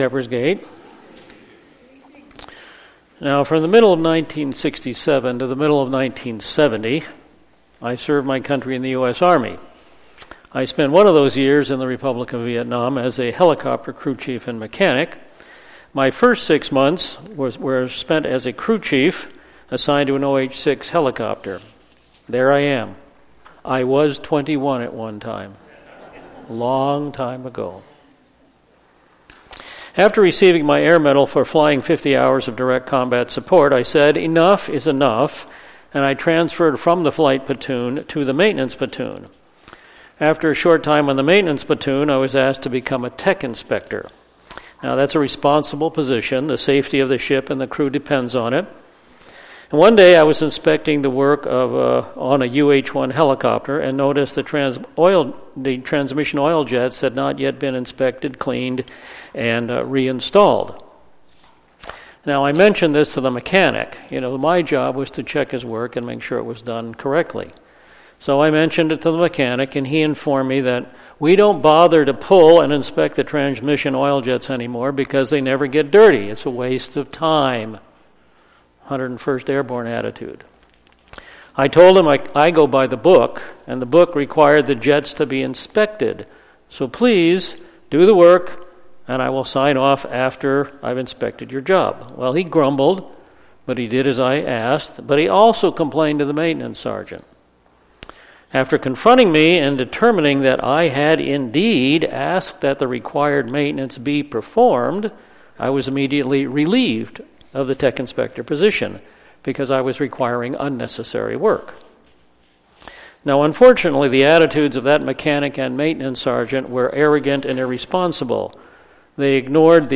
Amos 6:1-14 — Shepherd's Gate Church PDX